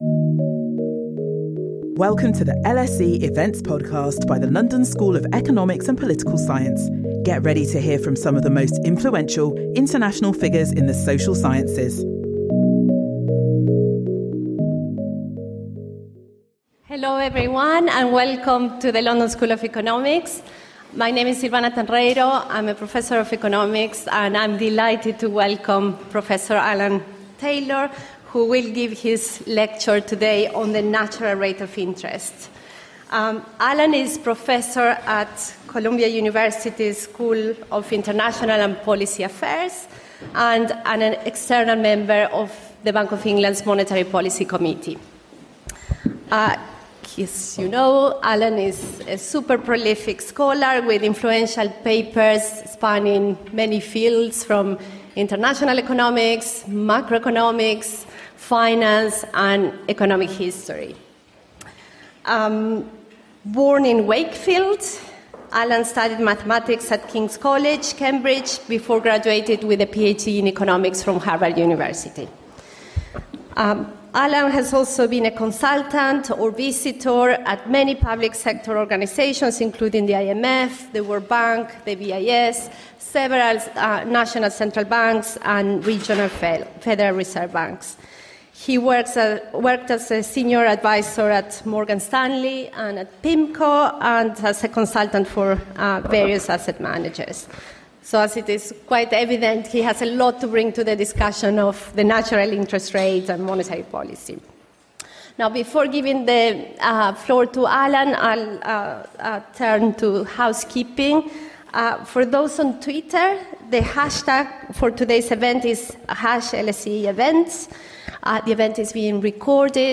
Join us for a special lecture by Alan Taylor, the newest member of the Bank of England's Monetary Policy Committee, on monetary policy.